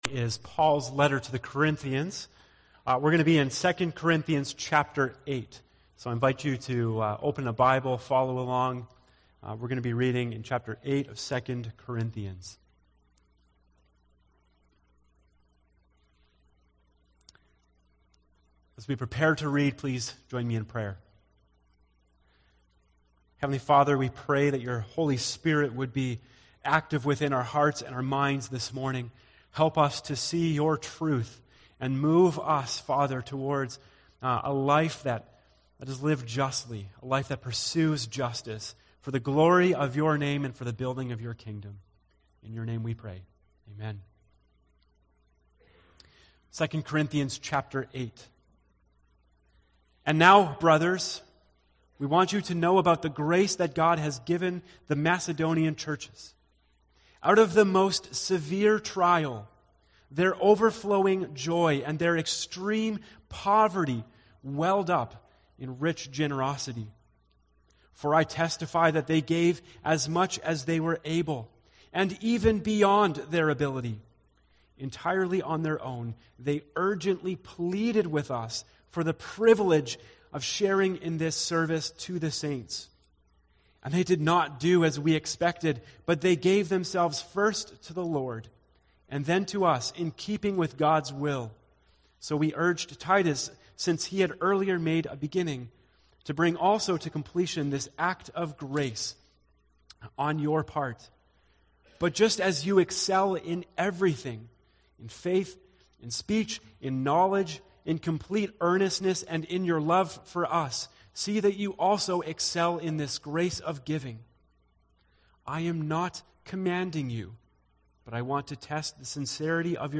A message from the series "Living Justly."